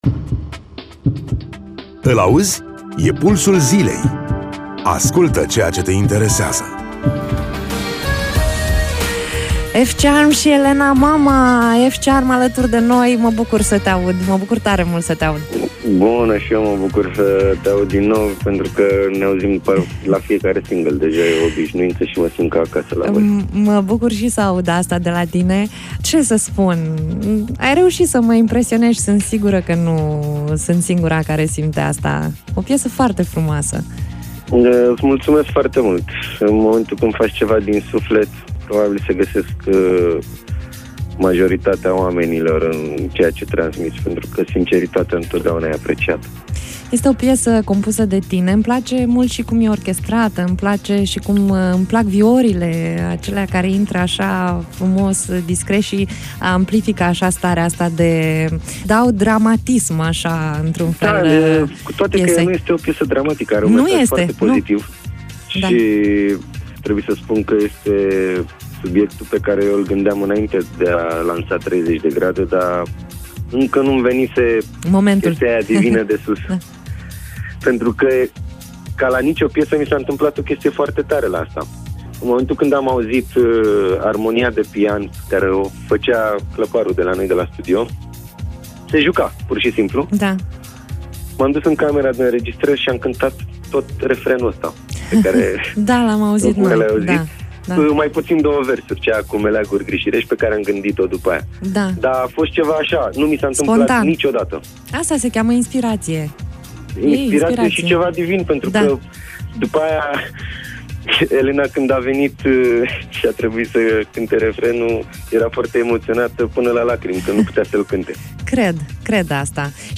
Interviu-F-Charm.mp3